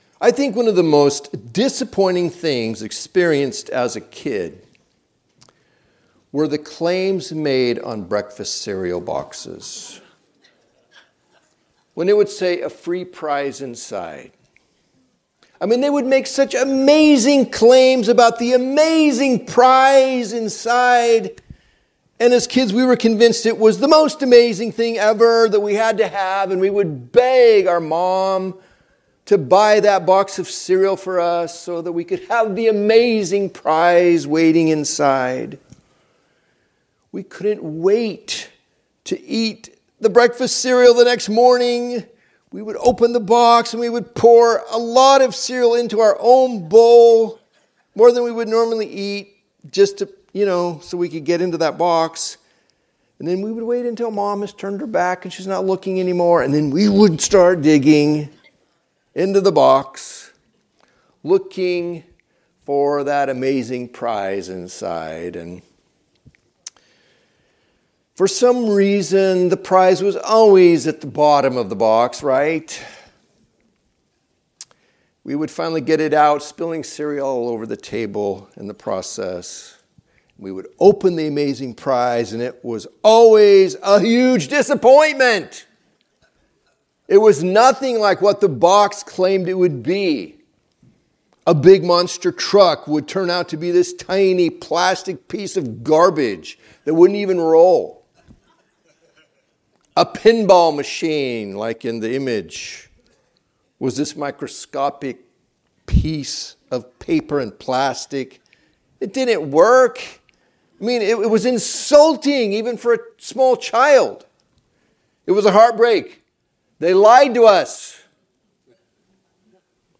Recordings of the teaching from the Sunday morning worship service at Touchstone Christian Fellowship. Tune in each week as we move through the Bible in a way that is both relevant and challenging.